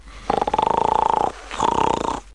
Snoring Sound Effect
snoring.mp3